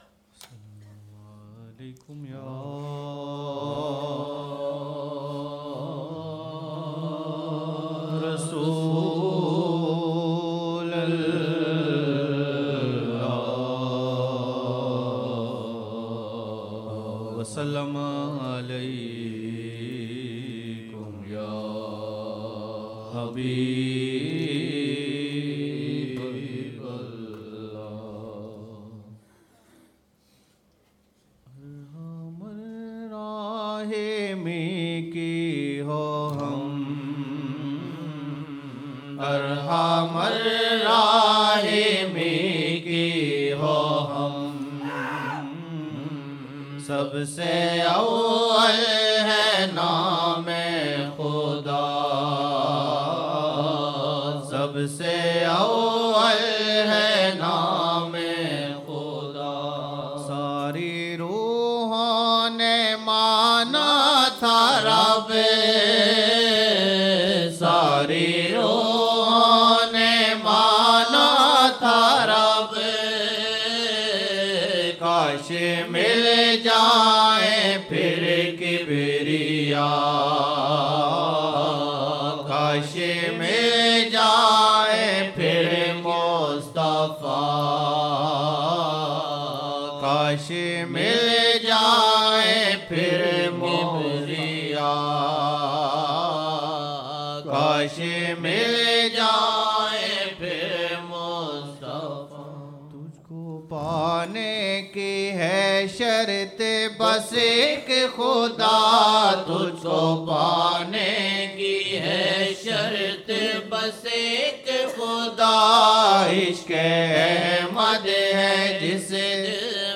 Chand naatia ashaar( sab se awal hay naam khuda, tujh ko pane ki hay shart, hay Rahman aap Raheem Ilahi) 2007-01-01 01 Jan 2007 Old Naat Shareef